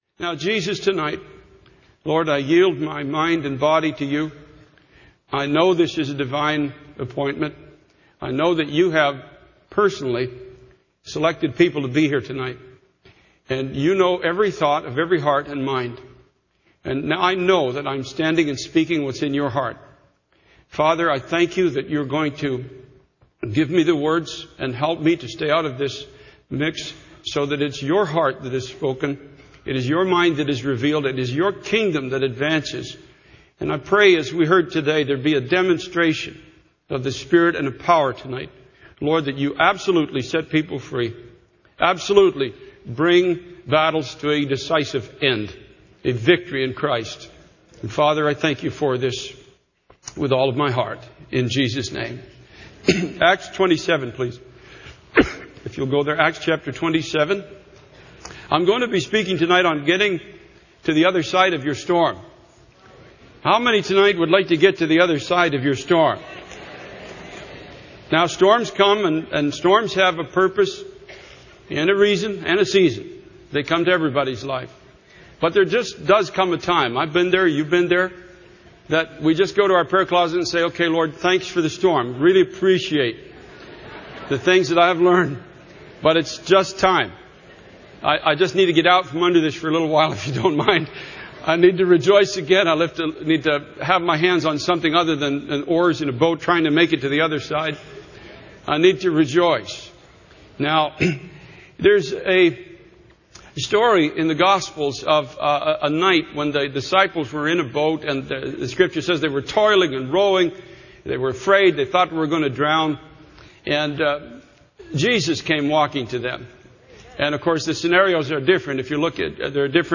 In this sermon, the speaker discusses the story of Paul and his journey to Rome. The speaker emphasizes the importance of following God's will rather than our own desires. He highlights how following our own will can lead to despair and hopelessness, while following God's will brings purpose and hope.